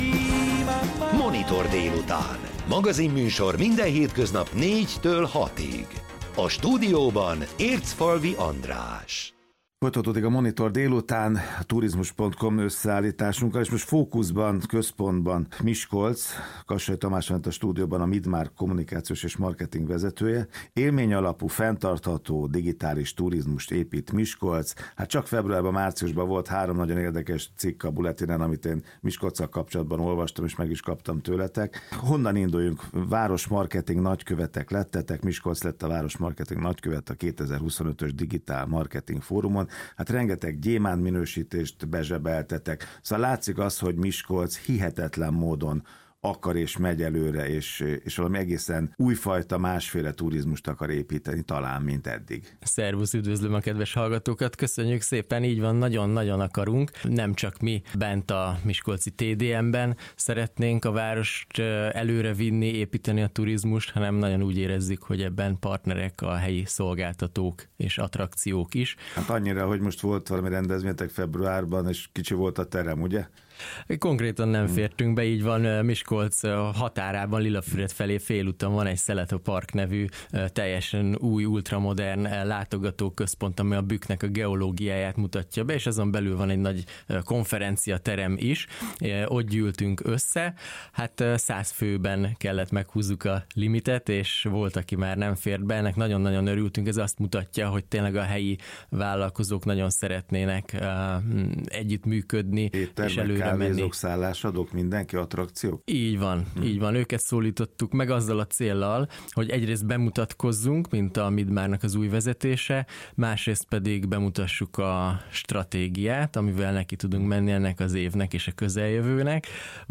A Trend FM Miskolcról szóló adása ide kattintva hallgatható meg.